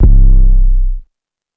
aw_808 (Olympic Melo).wav